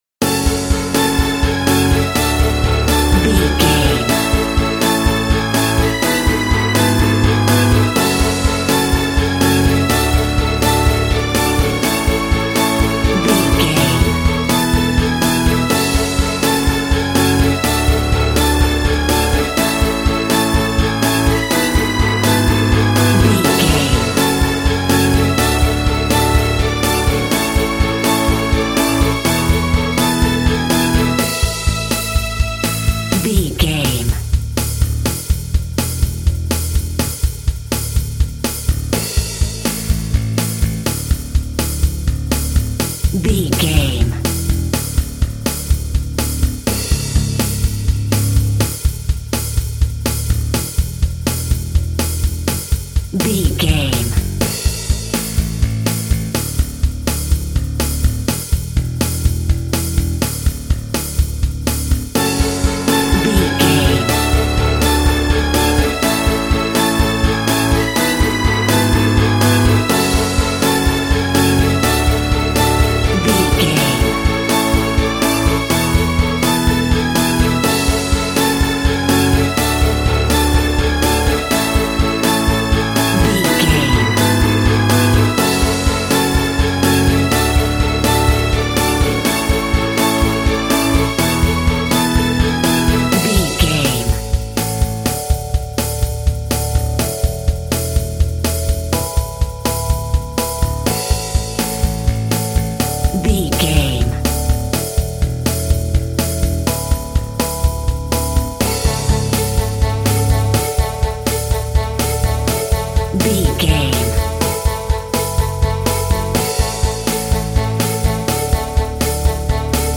Piano Pop Rock for Teens.
Uplifting
Ionian/Major
cheesy
acoustic guitar
drums
bass gutiar
electric guitar